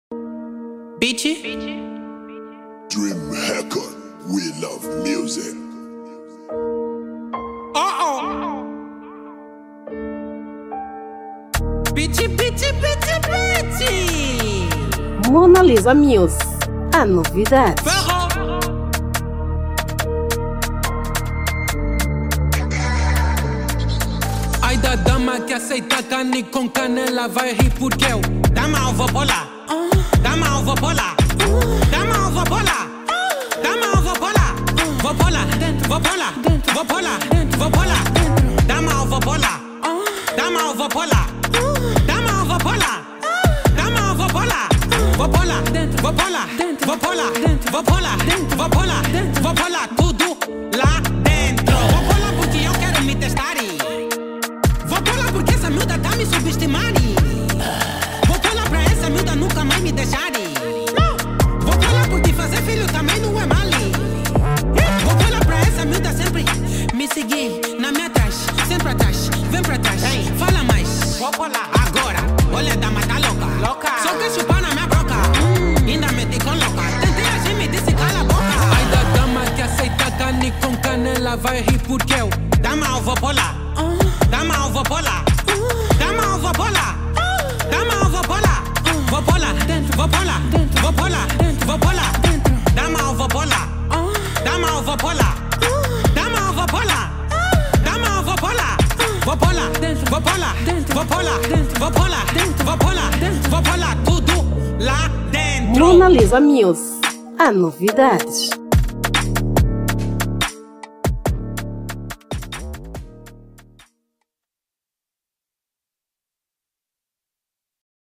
Gênero : Drill